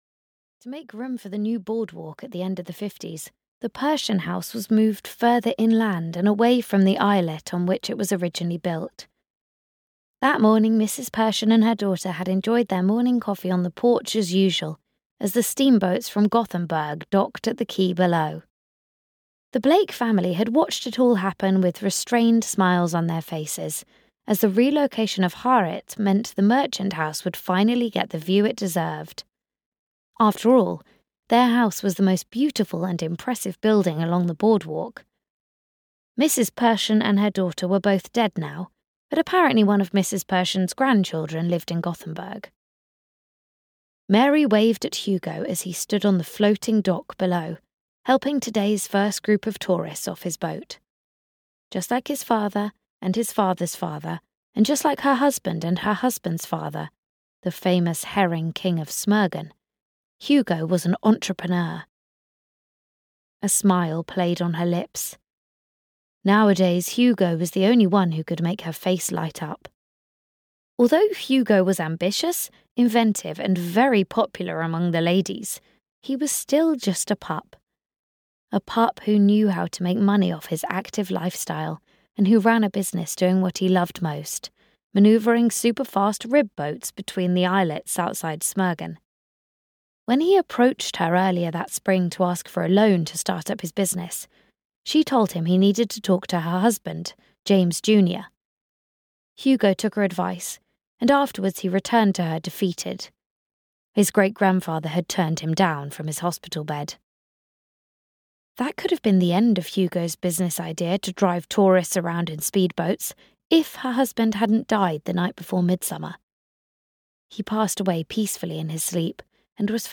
The Herring King (EN) audiokniha
Ukázka z knihy